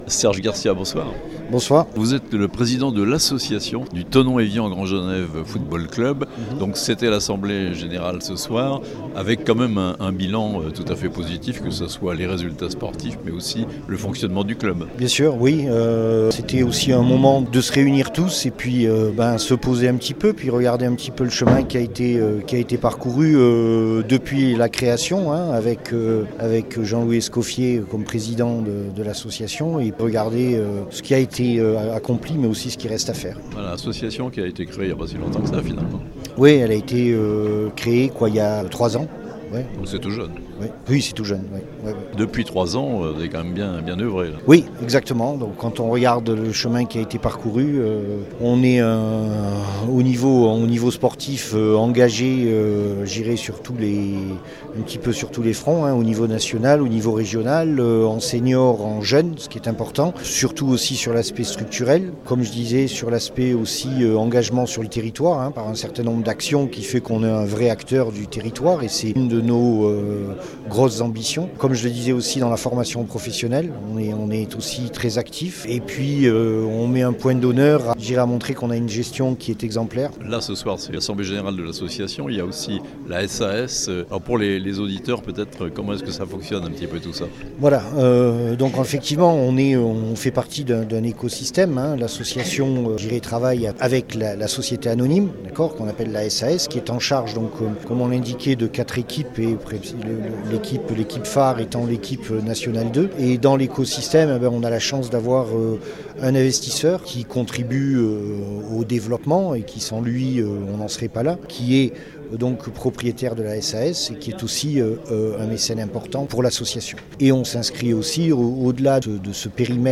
Thonon Evian Grand Genève F.C., un club de foot en pleine forme et débordant d'ambitions (interview)